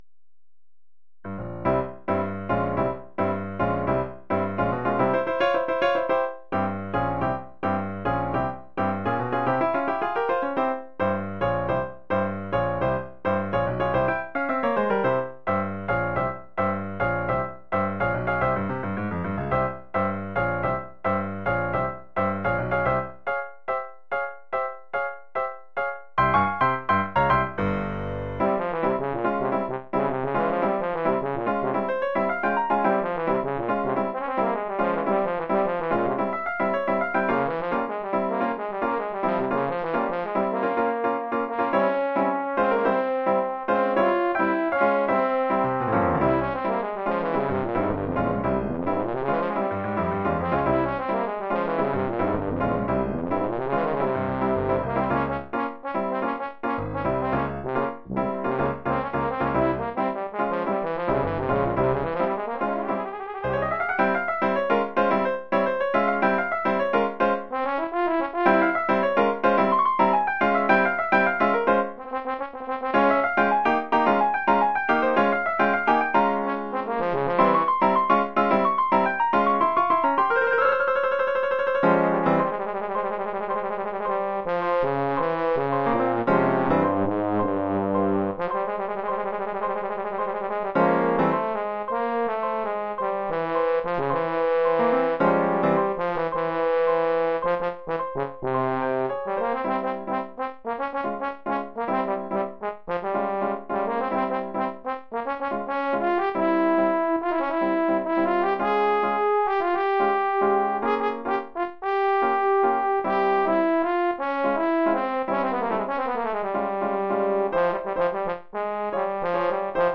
Oeuvre pour saxhorn basse / euphonium / tuba et piano.
tuba et piano.
Niveau : 3e cycle.